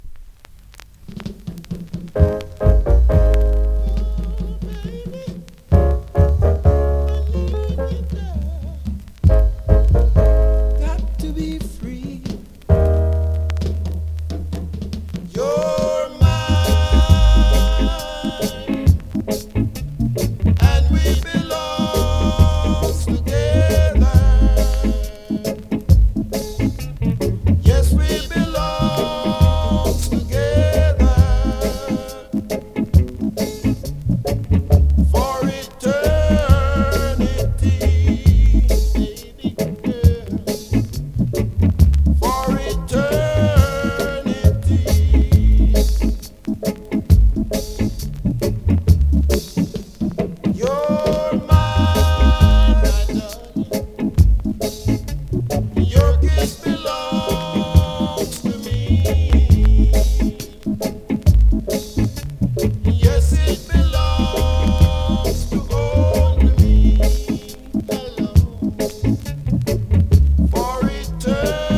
ホーム > 2016 NEW IN!!SKA〜REGGAE!!
スリキズ、ノイズ比較的少なめで